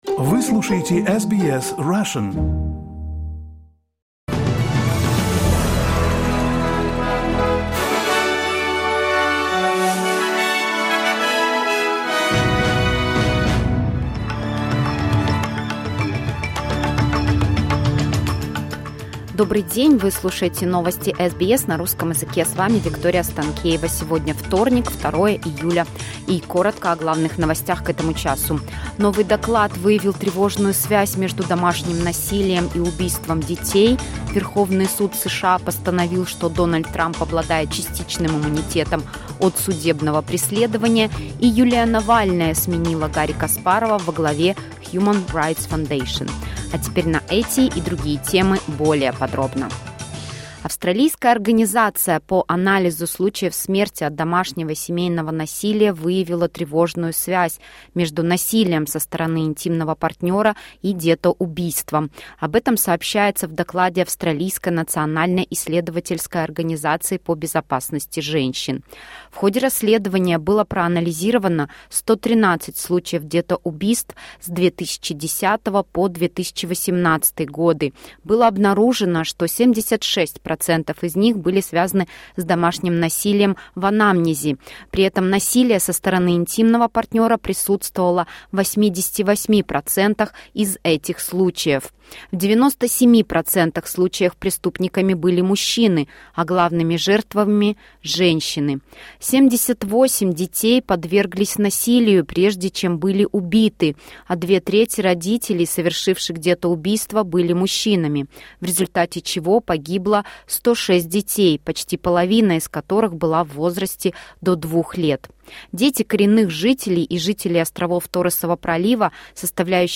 SBS News in Russian — 02.07.2024